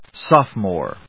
音節soph・o・more 発音記号・読み方
/sάfmɔɚ(米国英語), sˈɔfəm`ɔː(英国英語)/